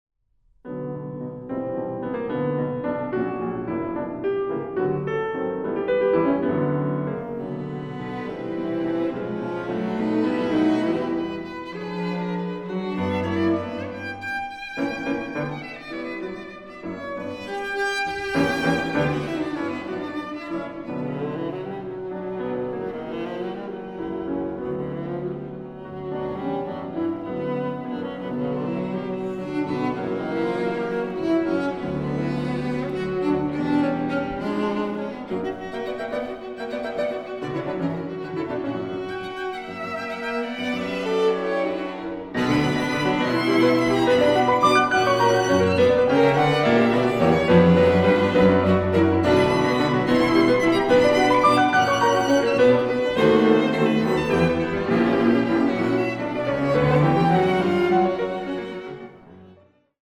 Klavierquartett